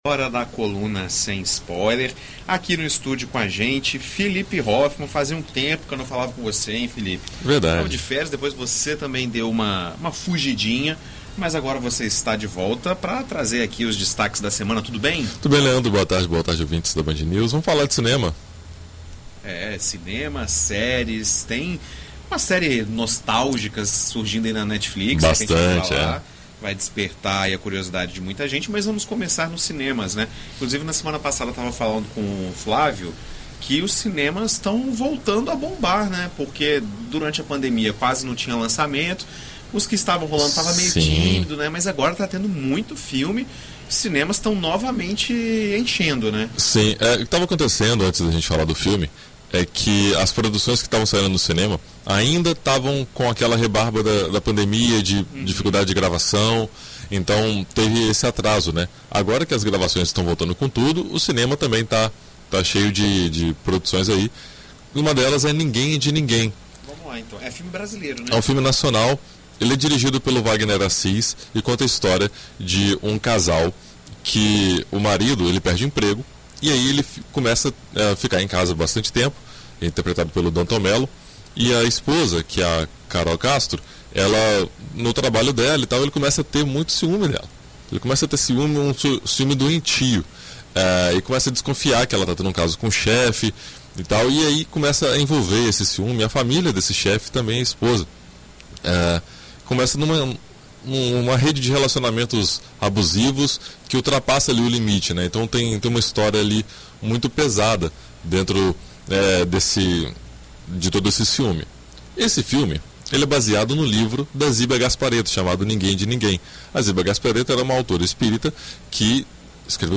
Na coluna Sem Spoiler desta quinta-feira (20) na BandNews FM ES